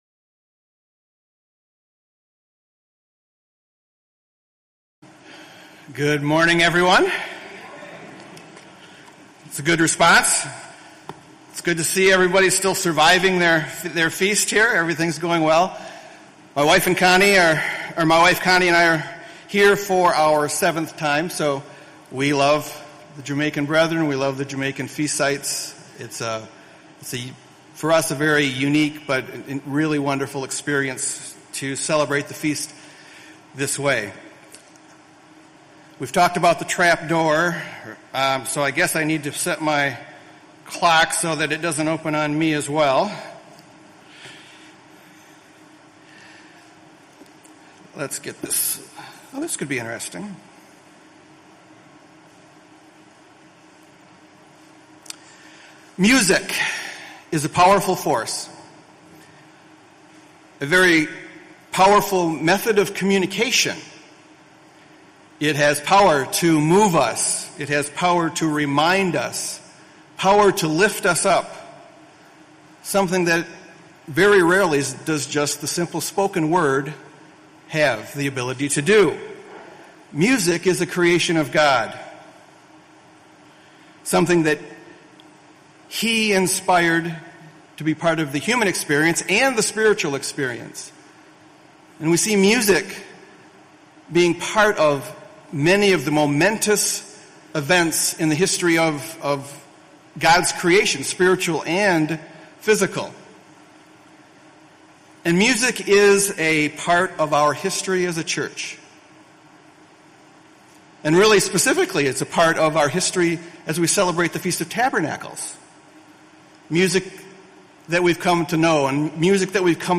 This sermon was given at the Montego Bay, Jamaica 2022 Feast site.